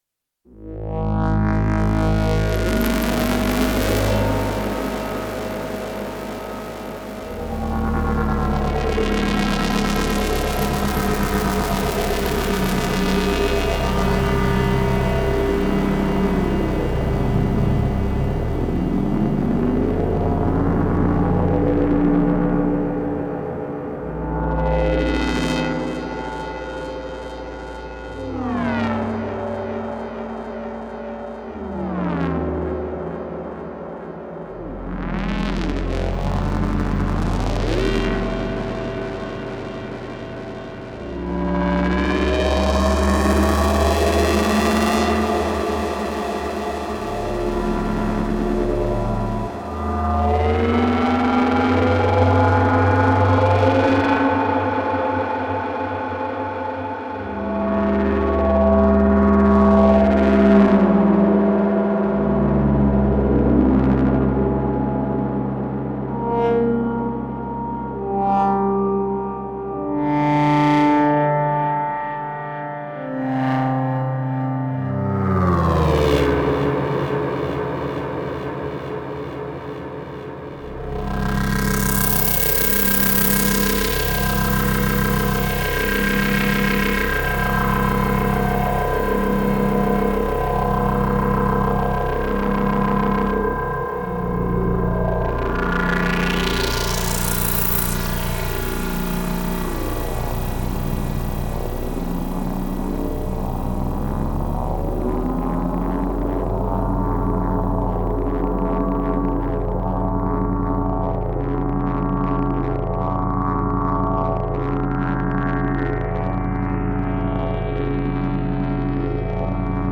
Aaand one more noodle, just pressing keys and moving knobs for animation
Just one toy track